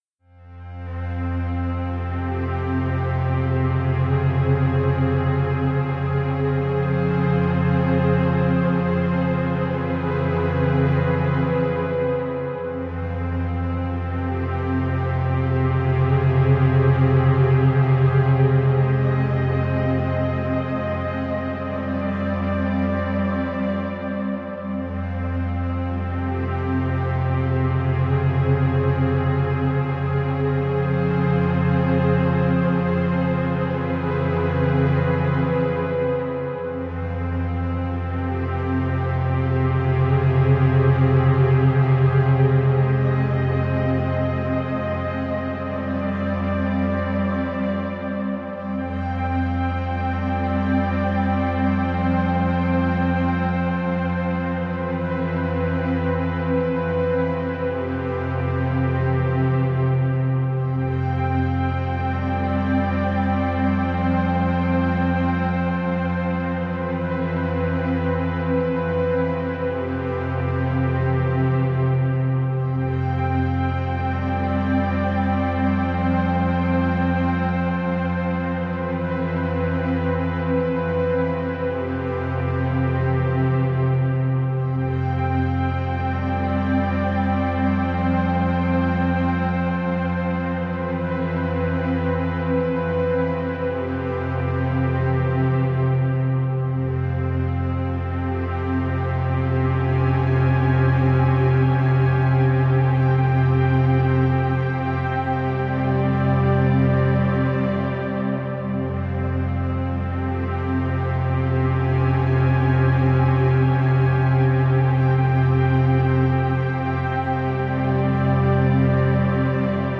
奇妙な雰囲気の曲です。【BPM80】